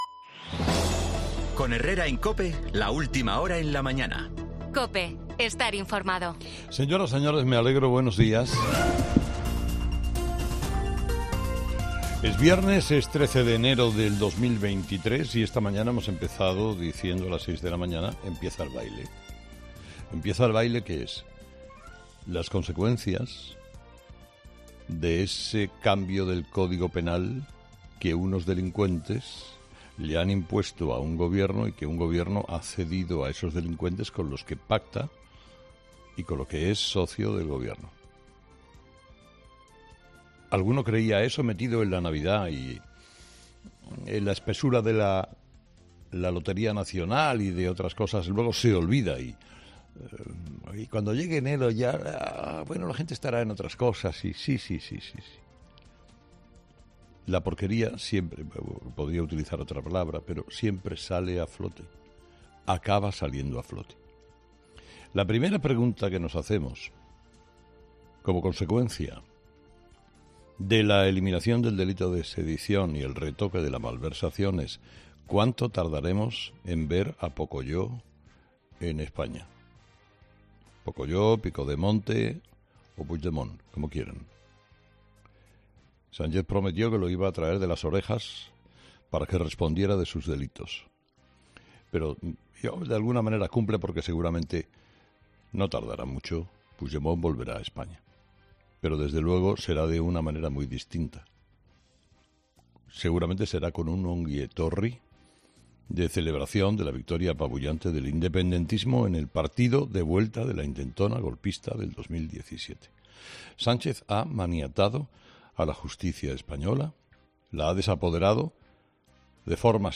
El comunicador analiza la justicia española en su monólogo de este viernes en 'Herrera en COPE'